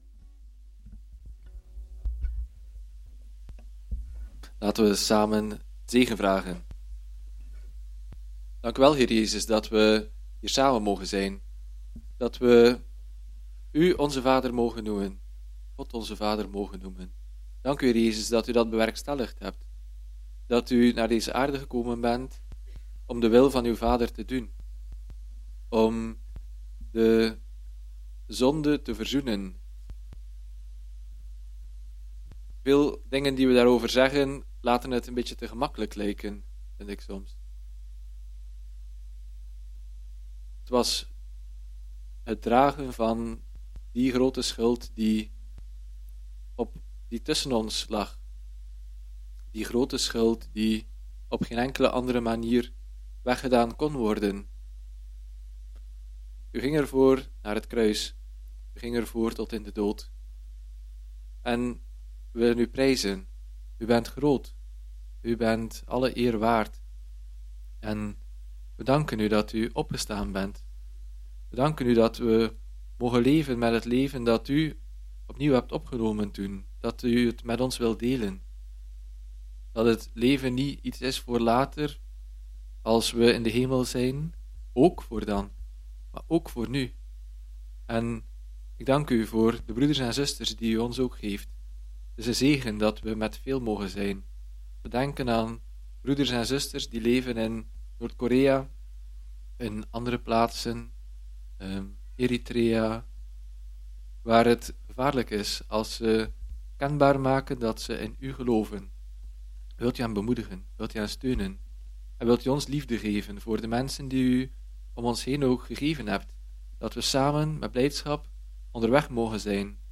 Getuigenis